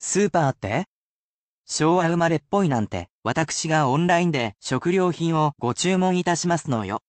[‘polite’ speech]